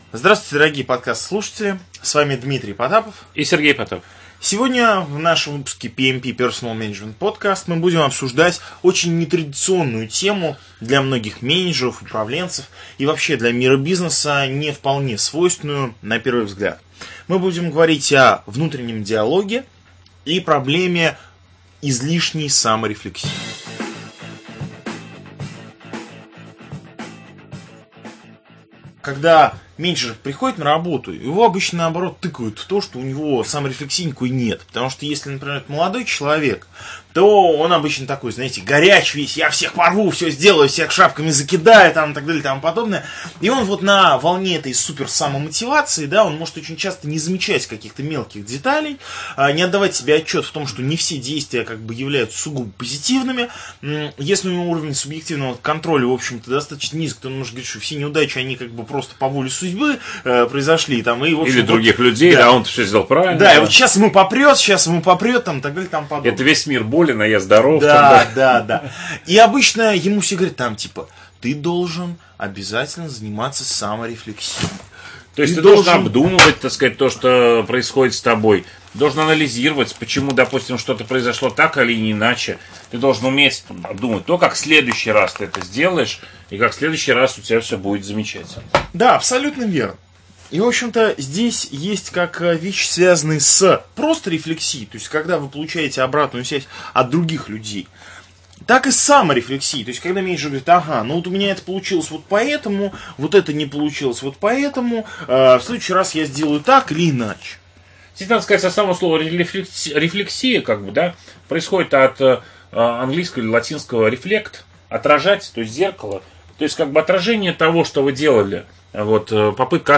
Аудиокнига Внутренний диалог: почему это плохо и как от этого избавиться?